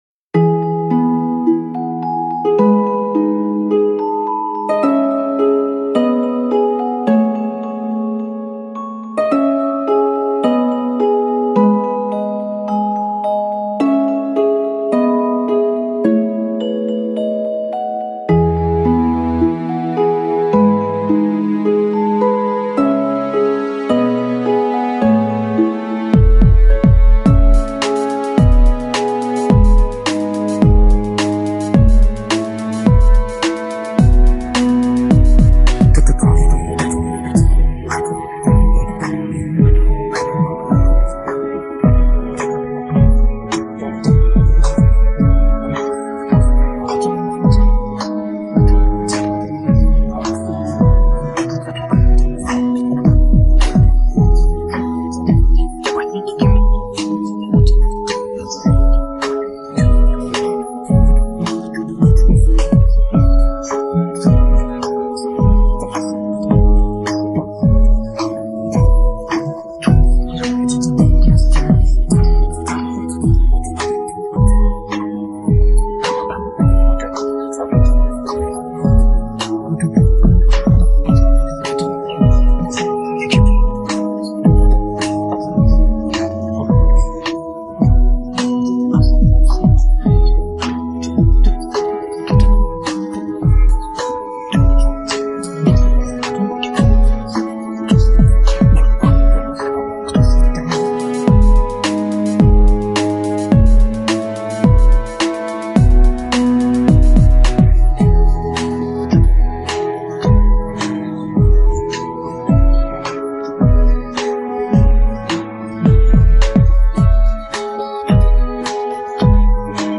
پخش نسخه بیکلام